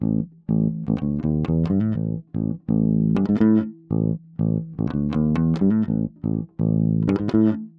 08 Bass PT4.wav